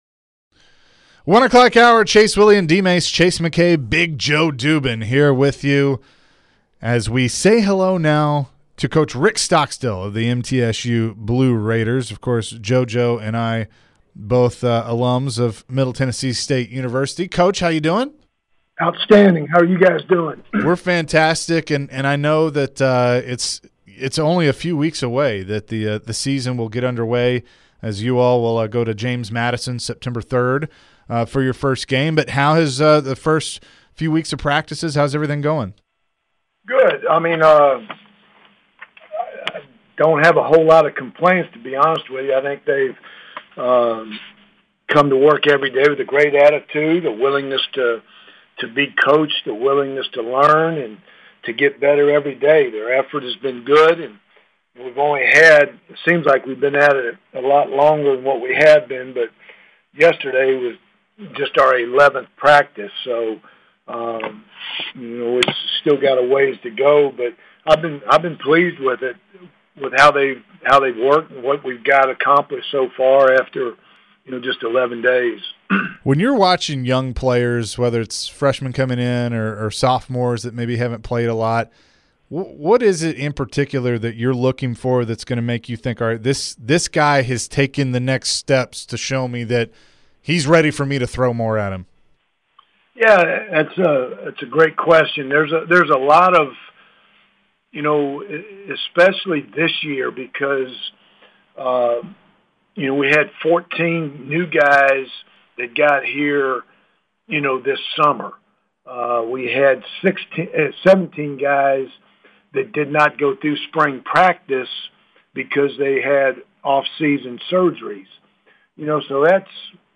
Rick Stockstill Full Interview (08-16-22)